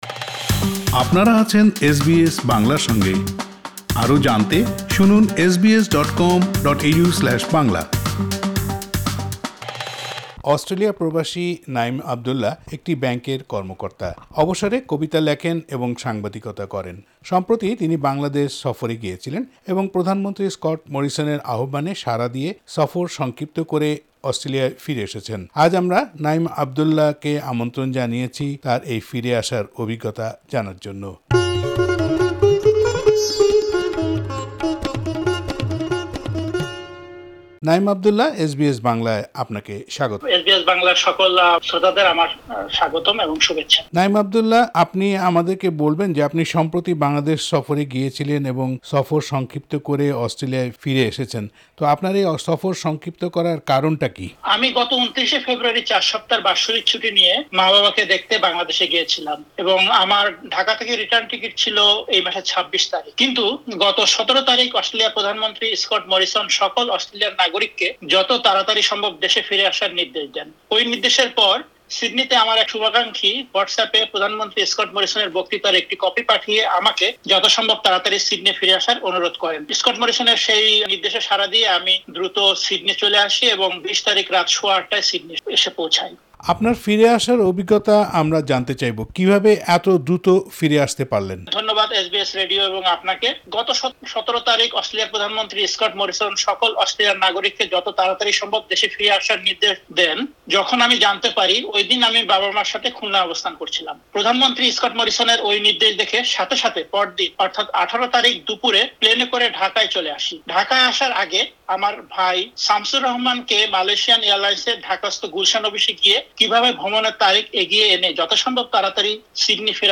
এস বি এস বাংলার কাছে তার ফিরে আসার অভিজ্ঞতা বর্ণনা করেন।